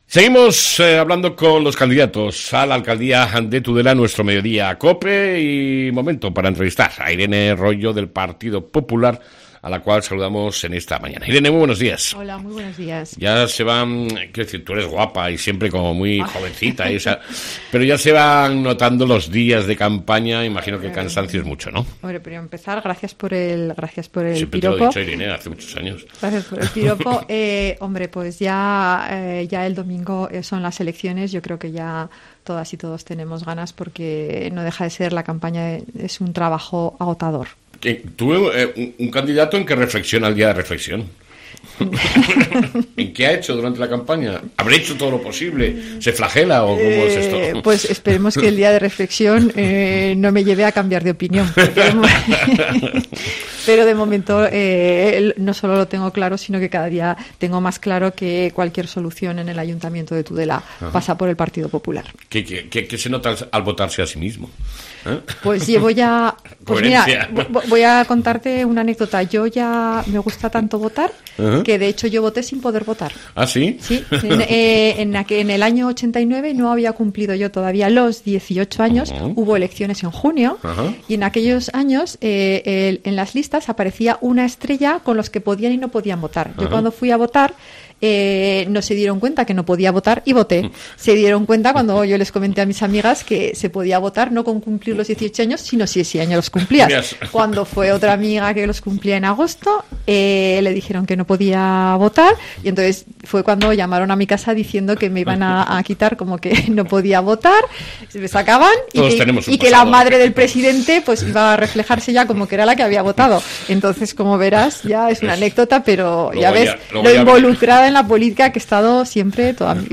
ENTREVISTA CON IRENE ROYO, CANDIDATA DEL PPN AL AYTO DE TUDELA Y AL GOBIERNO DE NAVARRA